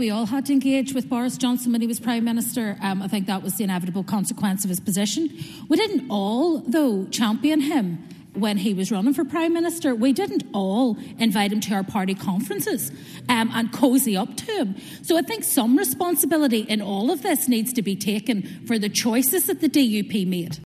Alliance party leader, Naomi Long, hit out at the DUP during the debate: